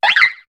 Cri de Larveyette dans Pokémon HOME.